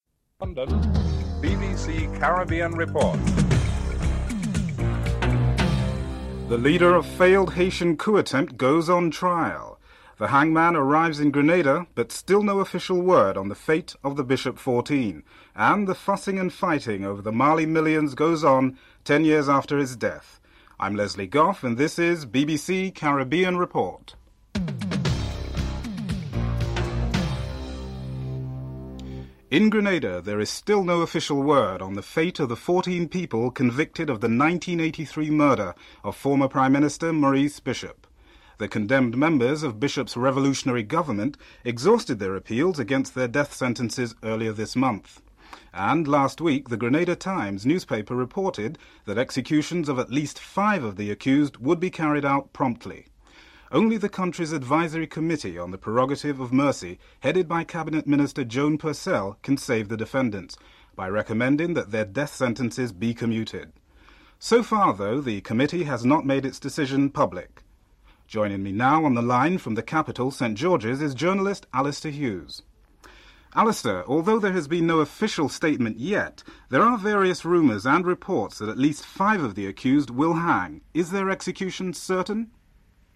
1. Headlines (00:00-00:28)